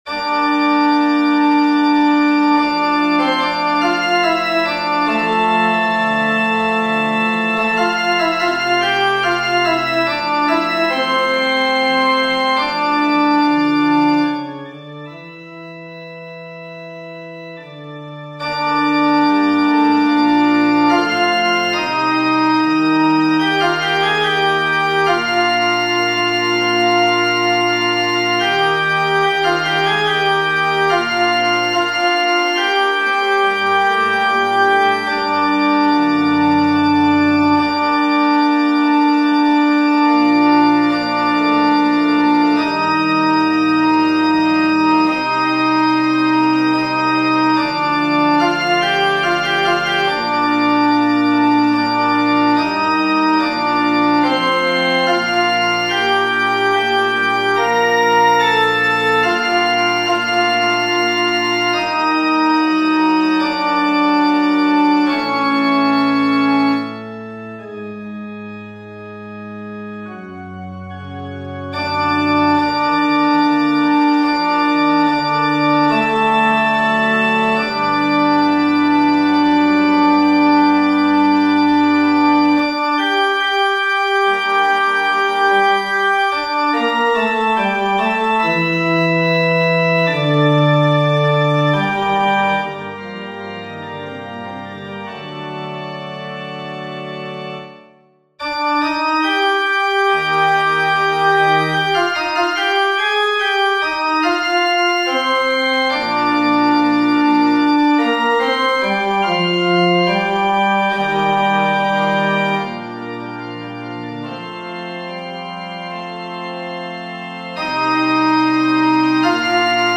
FF:VH_15b Collegium musicum - mužský sbor, FF:HV_15b Collegium musicum - mužský sbor